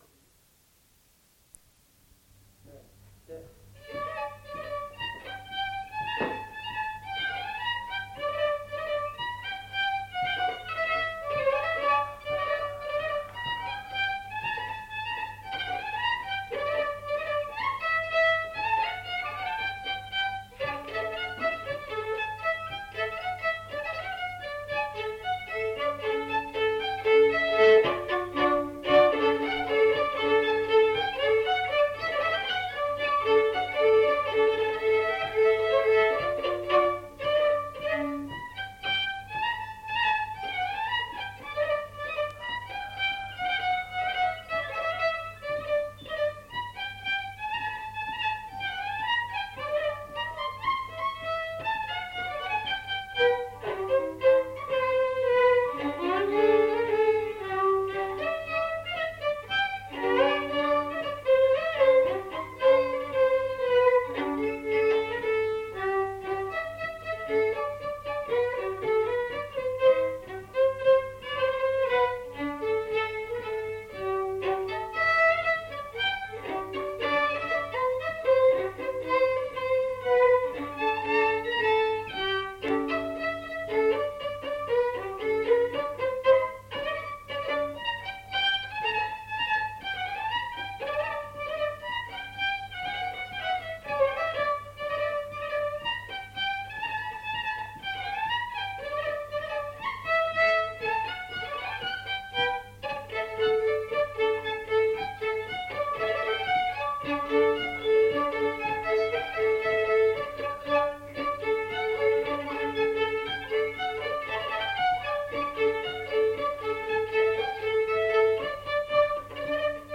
Polka
Aire culturelle : Lomagne
Genre : morceau instrumental
Instrument de musique : violon
Danse : polka